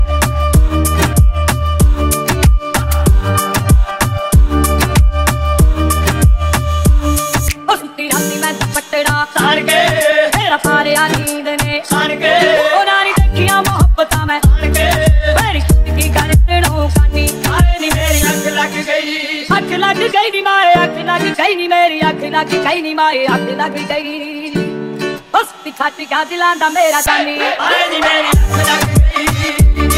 Regional Indian